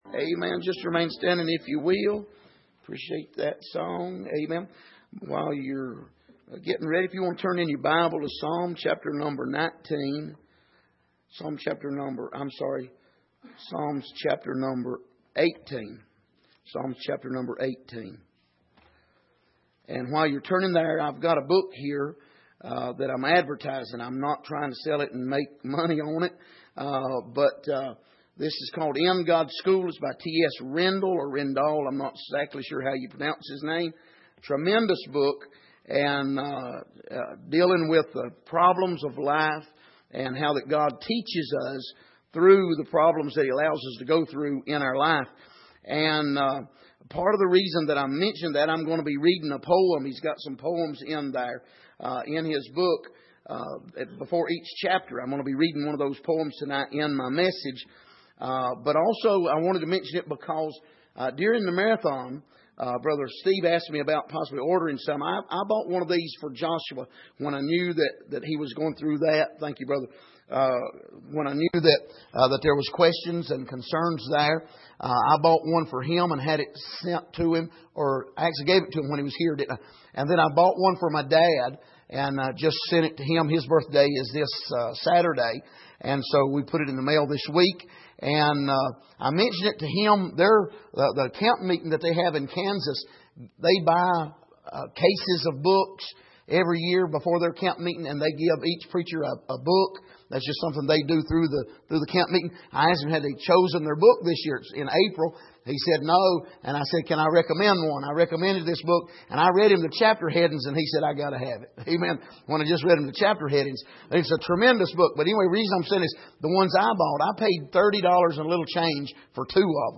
Miscellaneous Passage: Psalm 18:30 Service: Midweek As For God